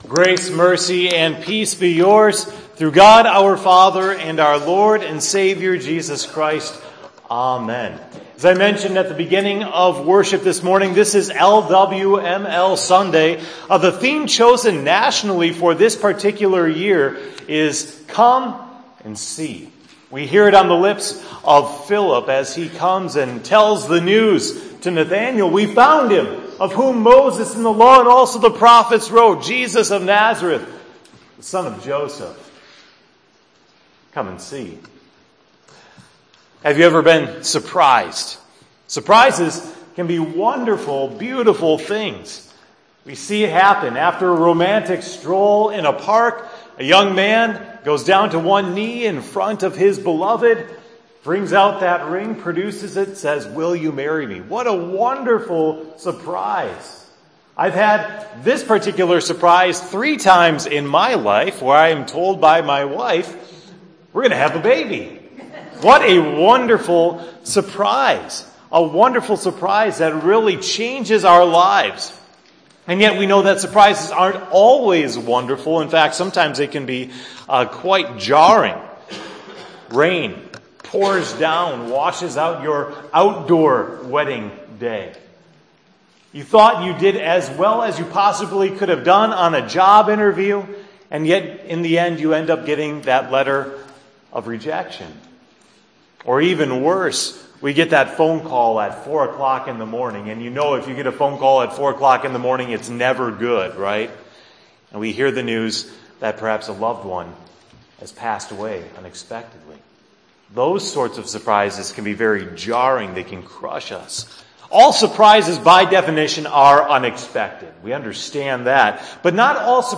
“Come and See!” – Sermon for 10/2